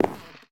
creaking_heart_step6.ogg